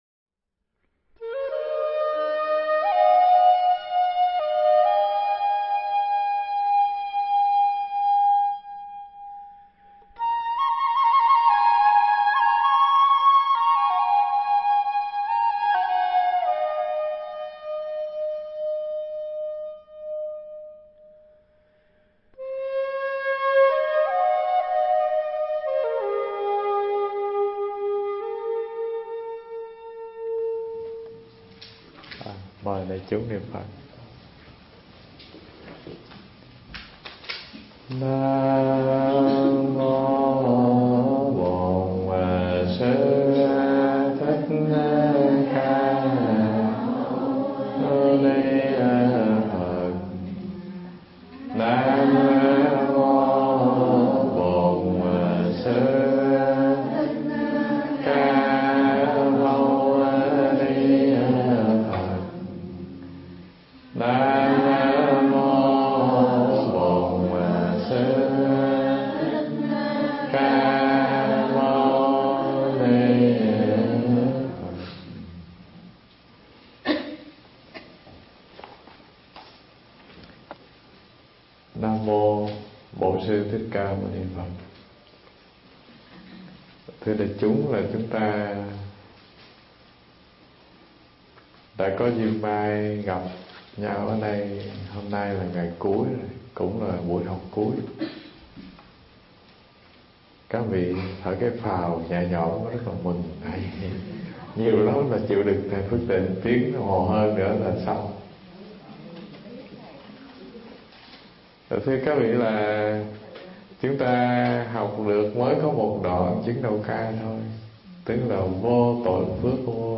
Nghe Mp3 thuyết pháp Chứng Đạo Ca 07 Chưa Từng Thêm Bớt Phần 1